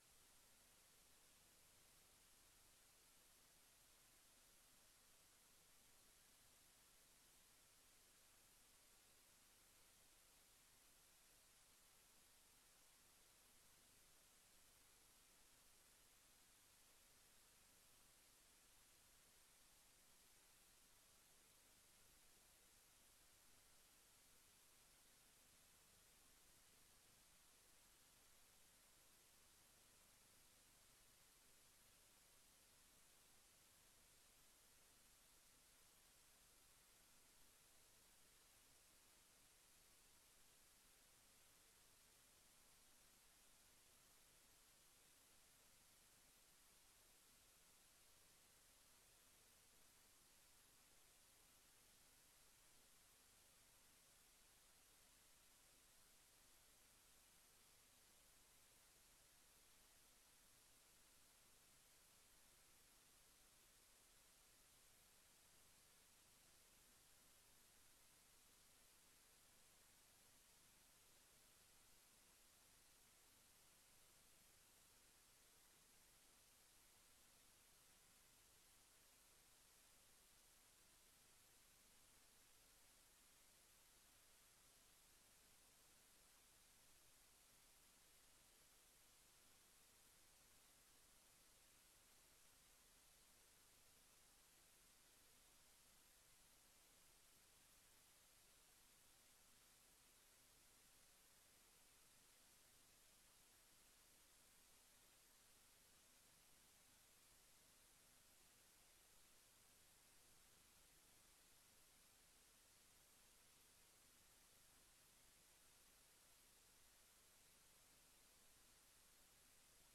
Bijzondere raadsvergadering: voordracht Burgemeester